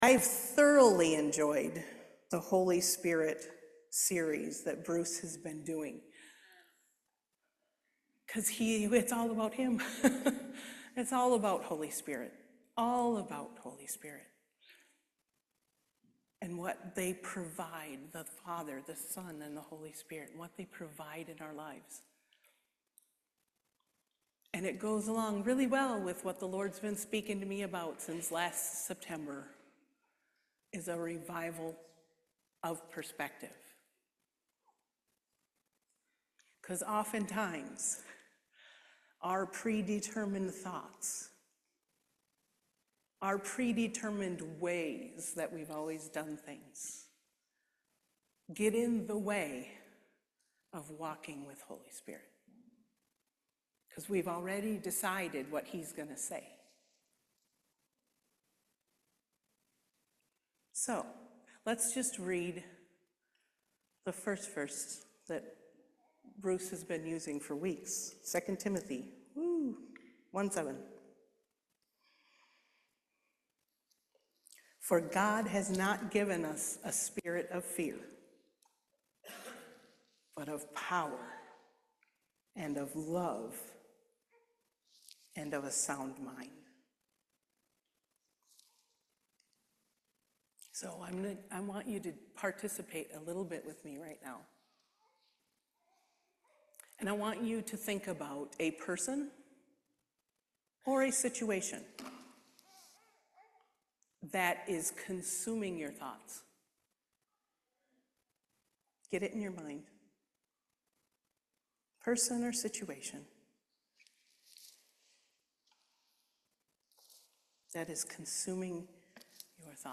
Service Type: Main Service